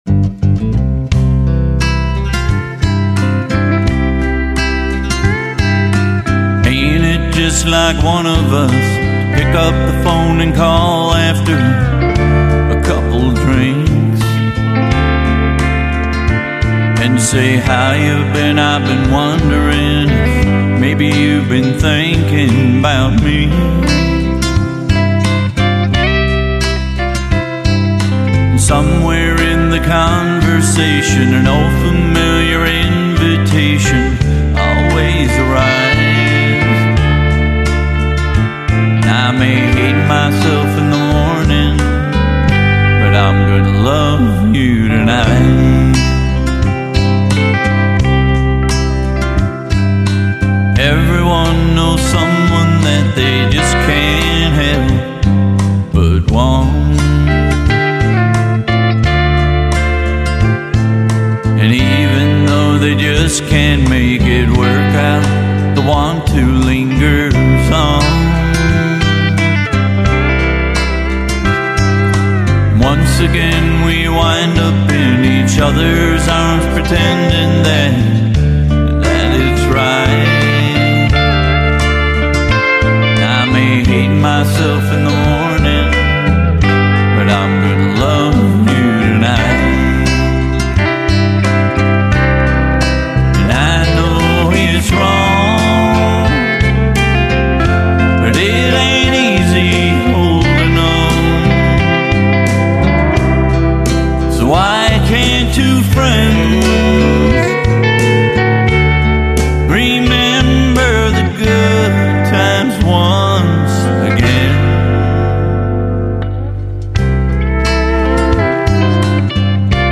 HonkyTonk / Outlaw / Classic Country
Band Demo